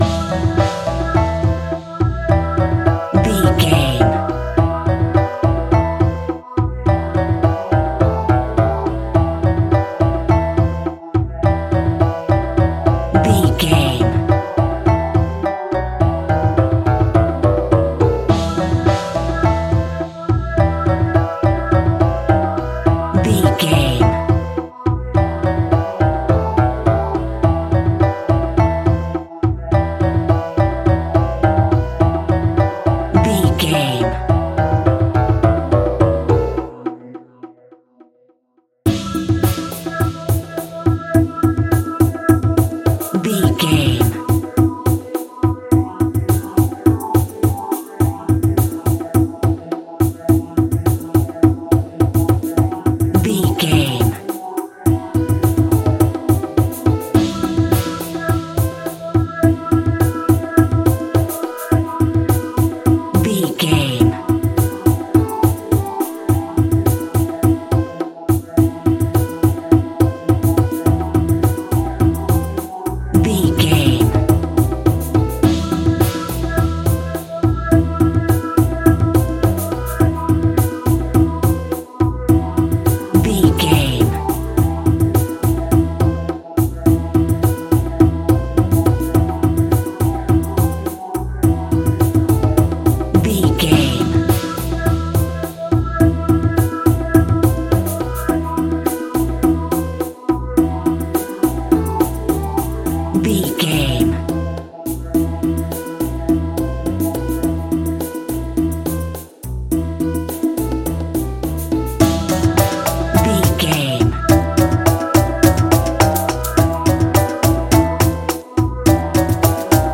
Aeolian/Minor
scary
ominous
dark
suspense
haunting
eerie
strings
percussion
synthesizer
ambience
pads